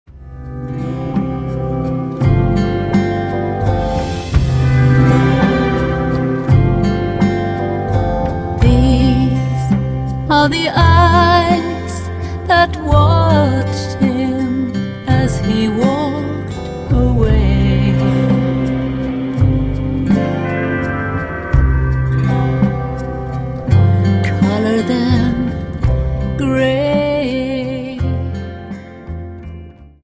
Dance: Slow Waltz 28 Song